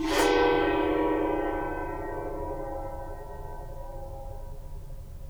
gongscrape_pp.wav